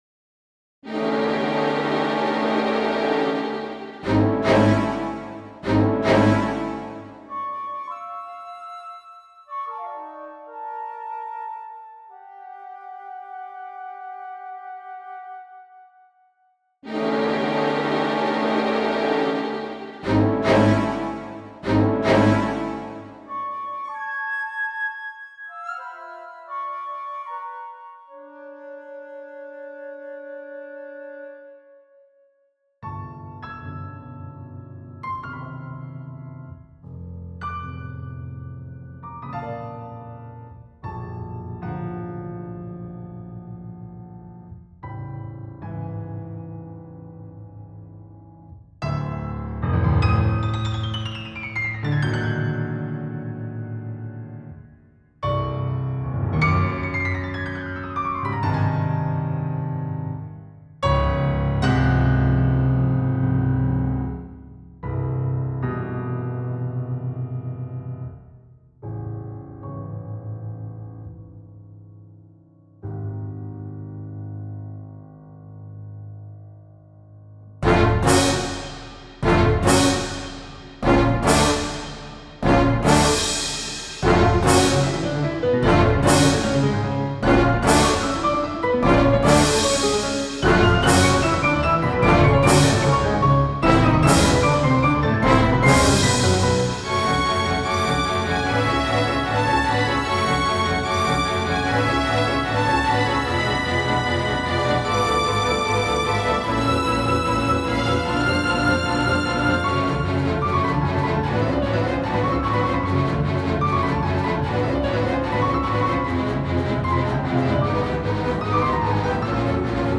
Rhapsody for Piano and Orchestra